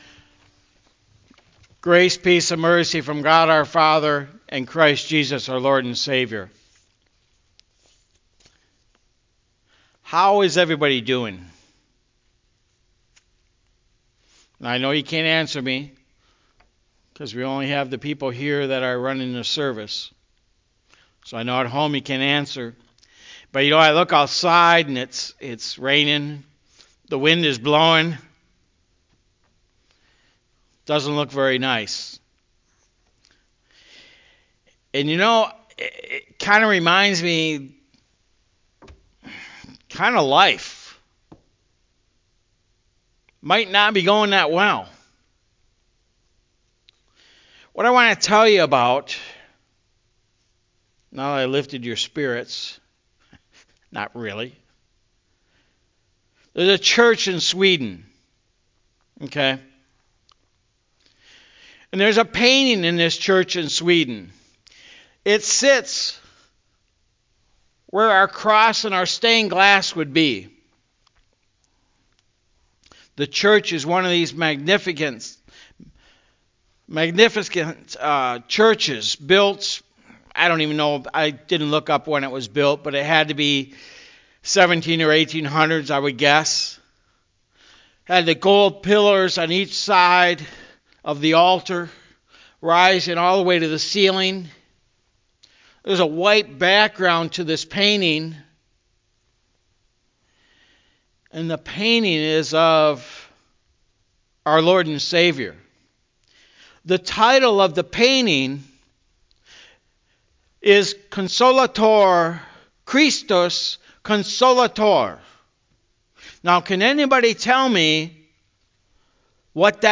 Sunday, February 07, 2021 | Sermon